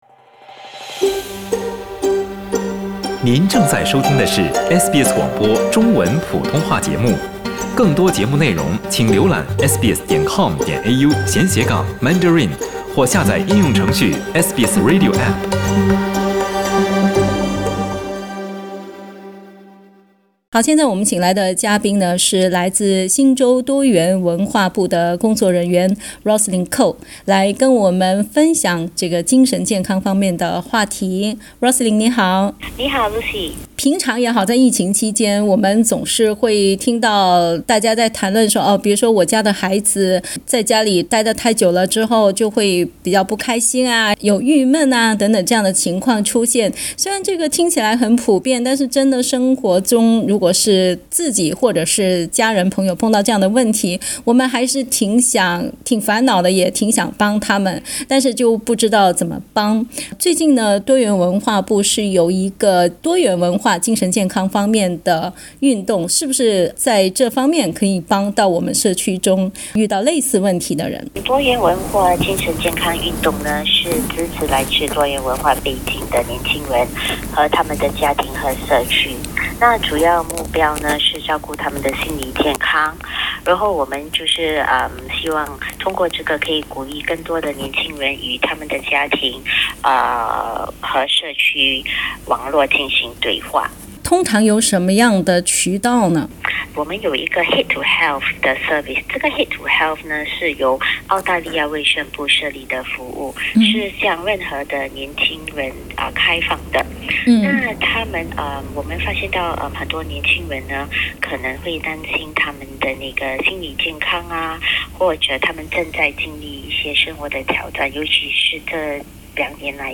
新州多元文化健康联络中心推出一个多元文化社区健康运动，鼓励新州的年轻人与他们的家人、朋友保持紧密联系。 （点击图片收听采访）